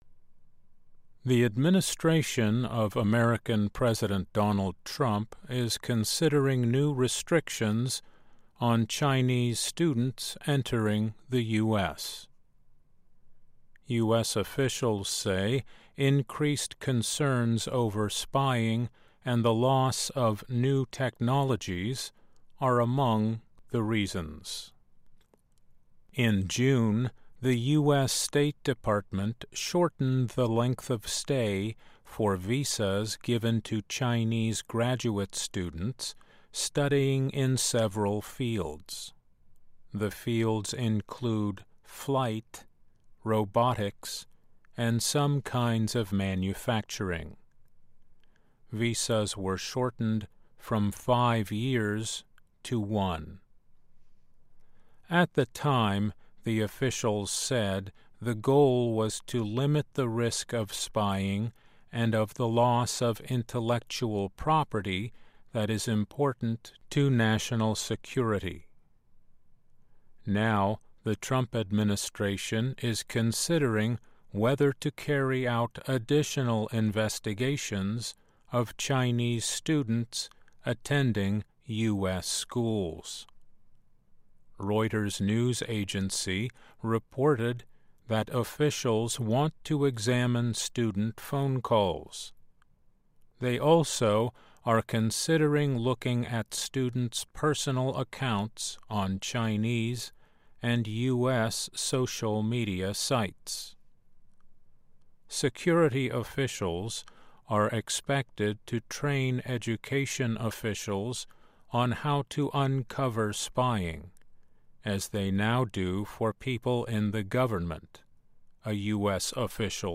慢速英语:美国考虑对中国留学生实施新限制